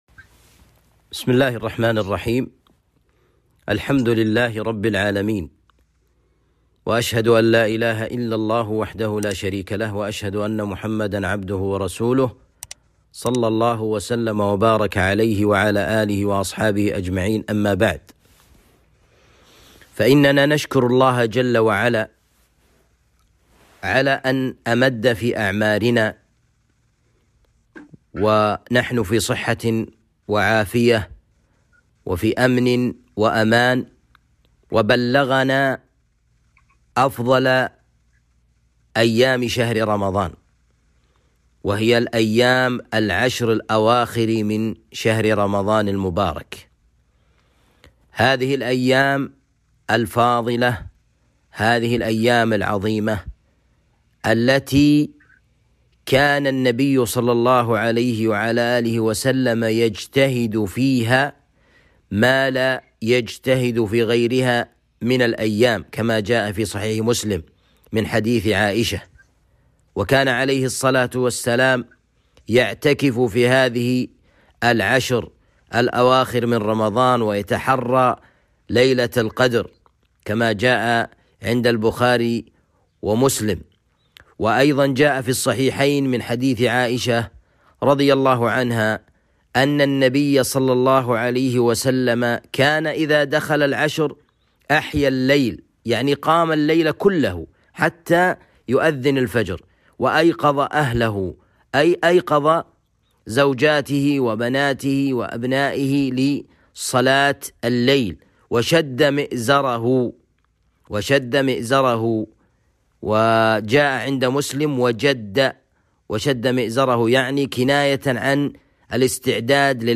محاضرة بعنوان فضل العشر الأواخر من رمضان وليلة القدر